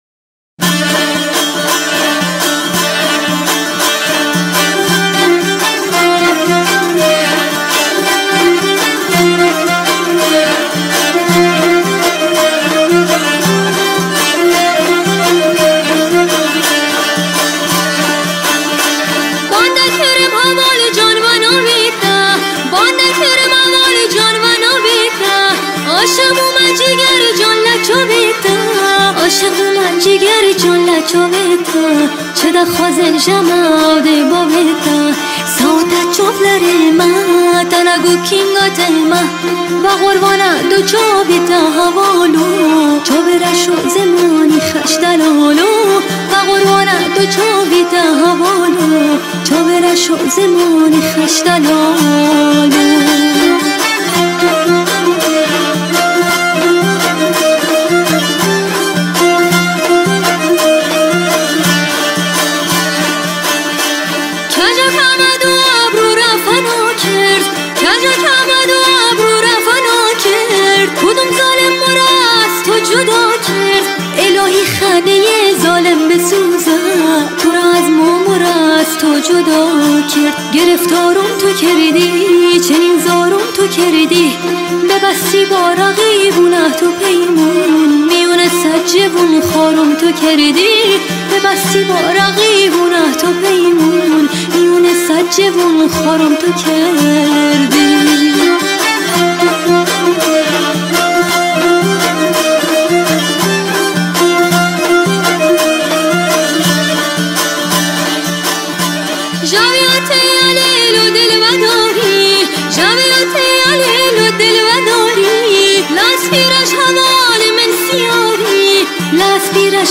نوازنده دوتار
نوازنده كمانچه
نوازنده دايره